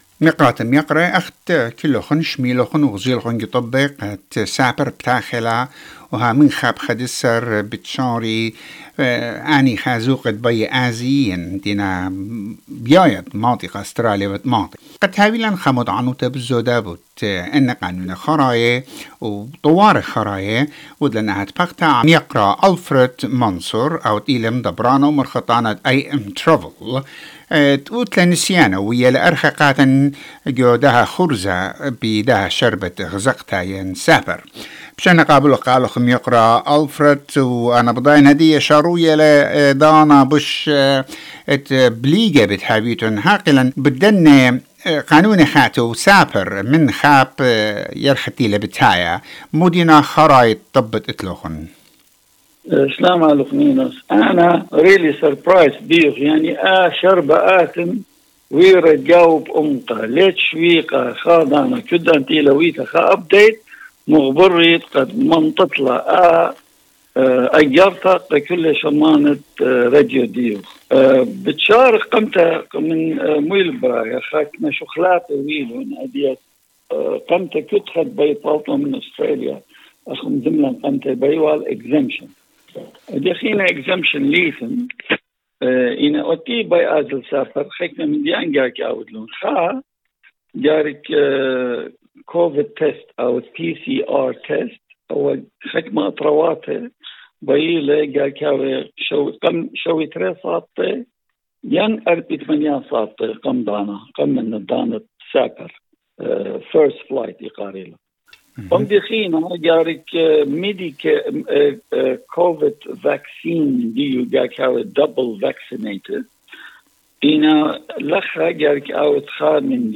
All these questions and more about border opening you will find in this interview